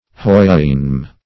Houyhnhnm \Hou*yhnhnm"\, n.